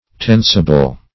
Search Result for " tensible" : The Collaborative International Dictionary of English v.0.48: Tensible \Ten"si*ble\, a. [See Tense , a.]